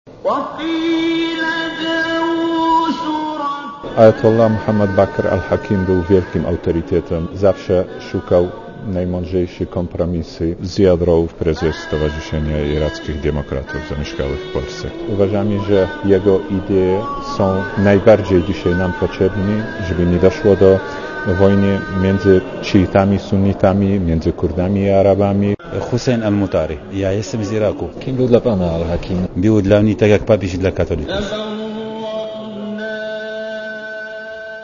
Na symboliczną stypę do podkrakowskich Tomaszowic przyjechało około 100 gości. Czytali Koran i wspominali duchowego przywódcę szyitów zamordowanego w piątkowym zamachu w Nadżafie.
Posłuchaj Irakijczyków wspominających swojego ajatollaha (134 KB)